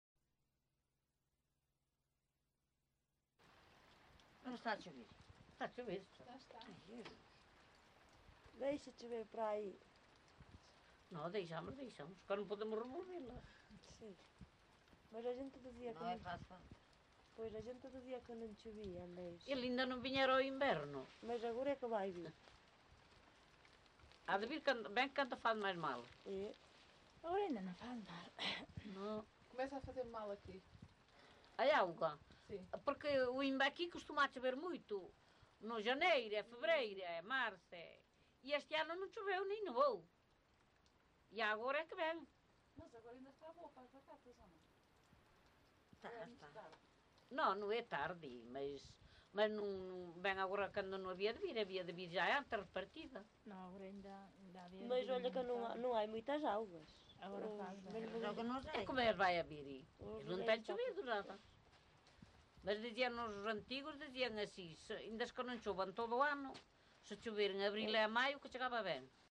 LocalidadeCastro Laboreiro (Melgaço, Viana do Castelo)